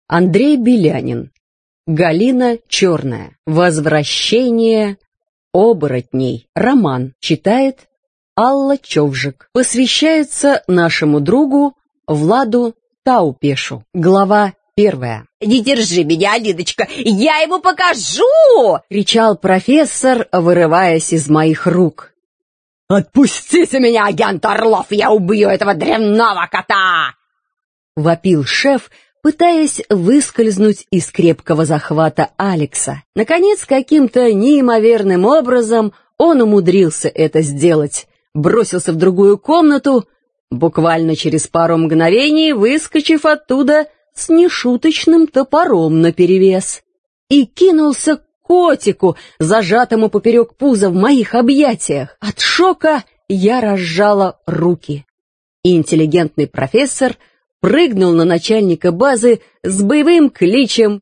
Аудиокнига Возвращение оборотней | Библиотека аудиокниг